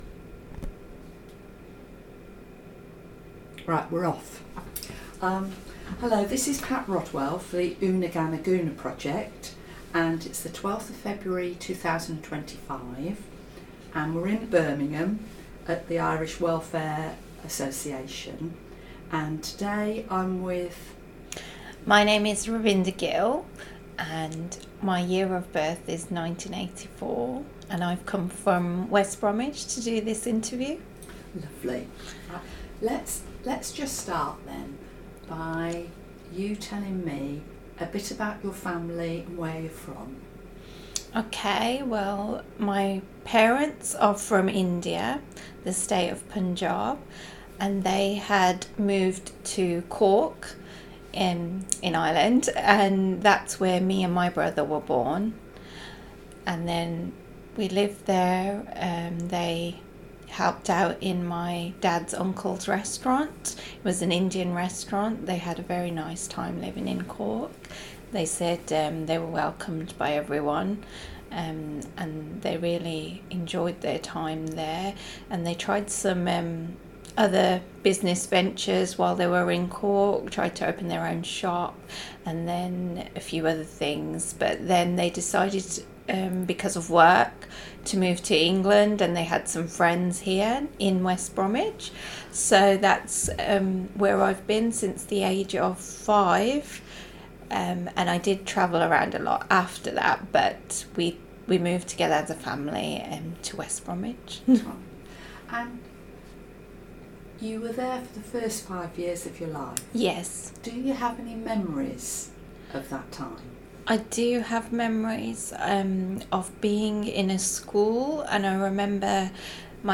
recorded in Birmingham, England
Interview